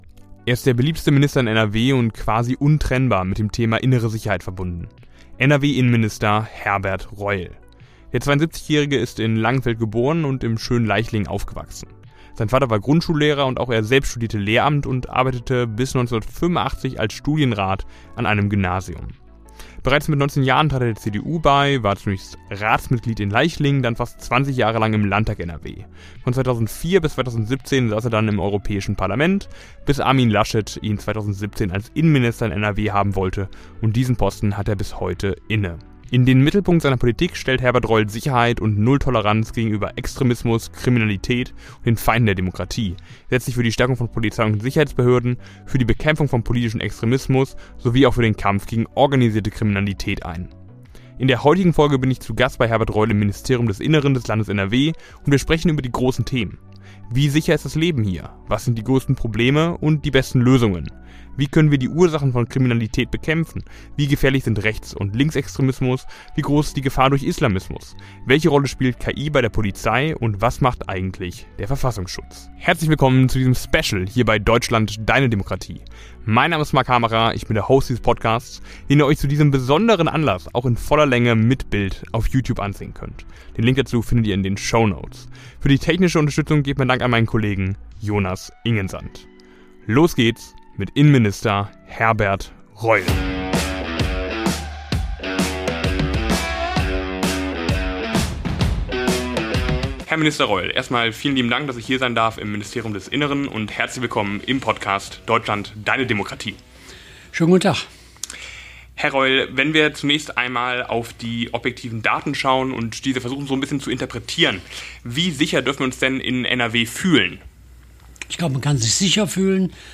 Das große Interview mit Herbert Reul (CDU) ~ Deutschland Deine Demokratie Podcast
In der heutigen Folge bin ich zu Gast bei Herbert Reul im Ministerium des Innen des Landes NRW und wir sprechen über die großen Themen: Wie sicher ist das Leben hier?